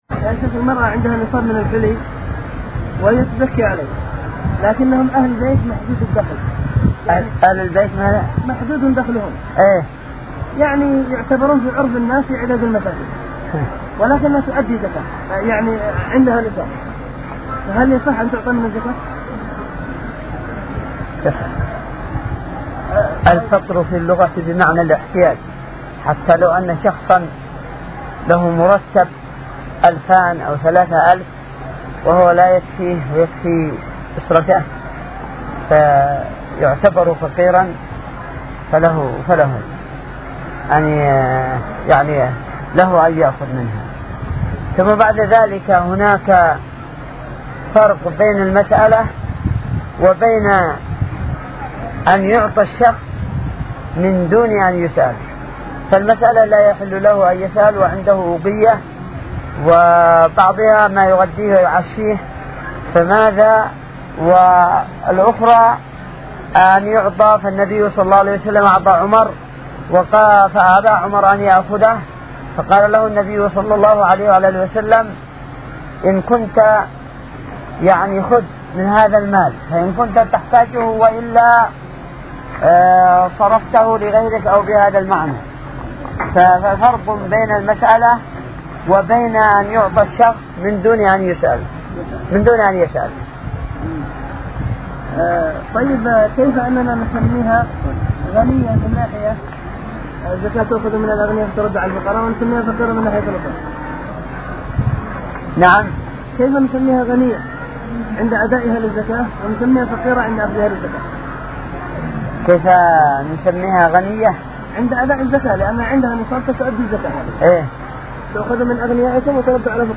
------------- من شريط : ( جلسة مع الزائرين )